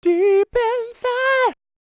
DD FALSET027.wav